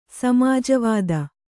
♪ samāja vāda